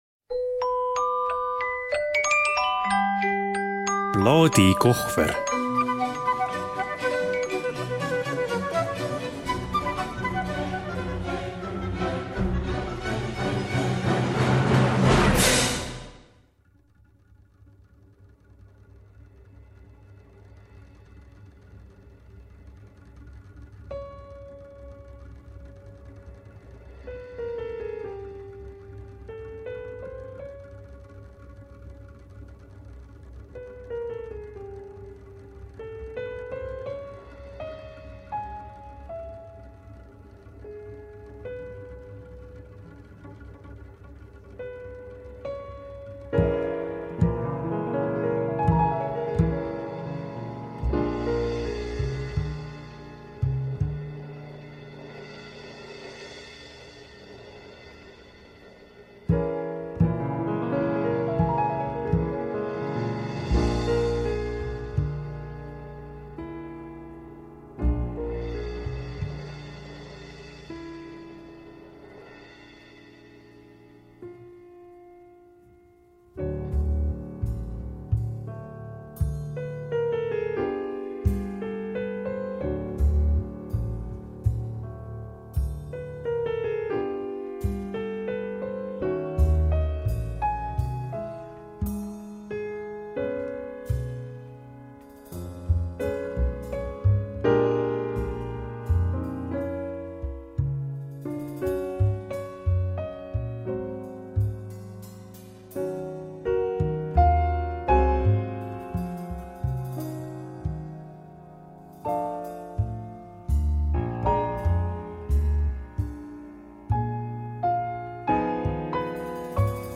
Intervjuu - Suurlinna tuled.